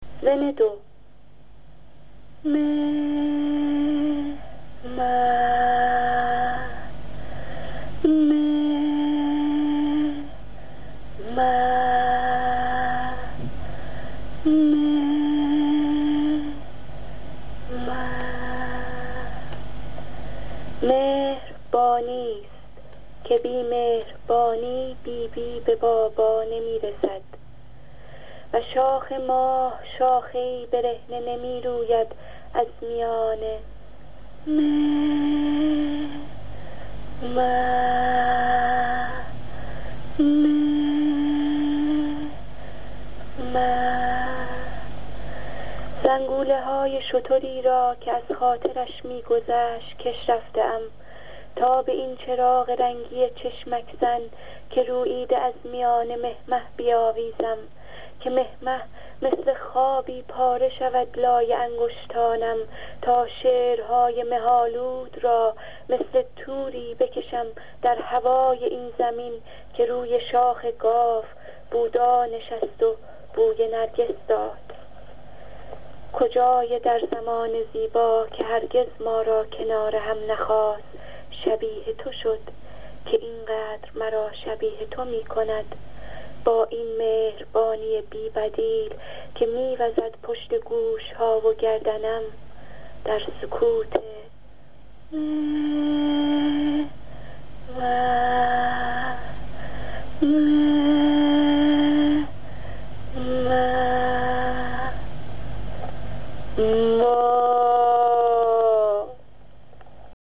برای شنیدن این شعر با صدای شاعراین جا کلیک کنید